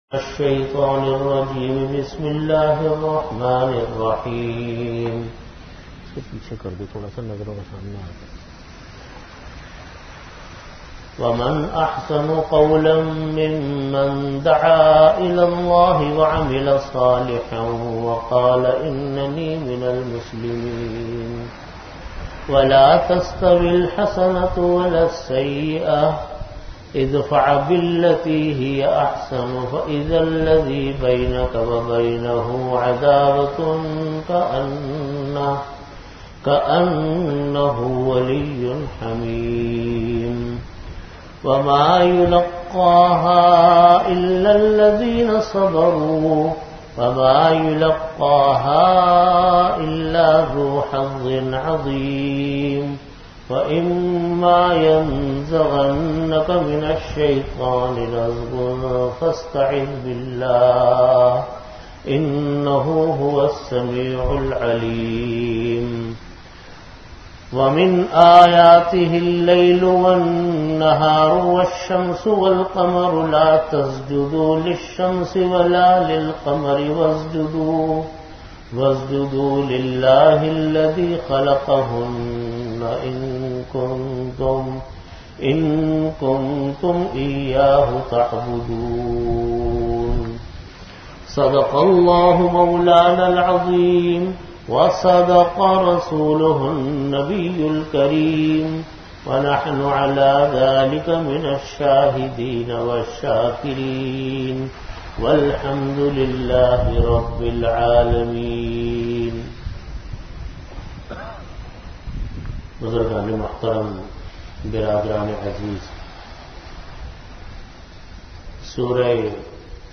An audio bayan
Originally delivered in After Asar Prayer at Jamia Masjid Bait-ul-Mukkaram, Karachi.
Venue: Jamia Masjid Bait-ul-Mukkaram, Karachi